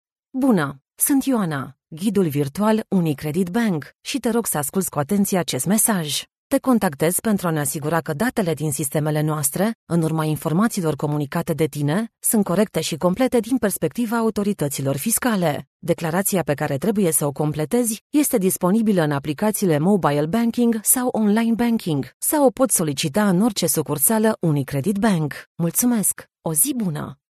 Naturelle, Accessible, Fiable, Commerciale, Corporative
Guide audio
Thanks to her extensive career in broadcasting, her voice has been trained for reliable authenticity and clarity.